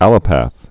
(ălə-păth) also al·lop·a·thist (ə-lŏpə-thĭst)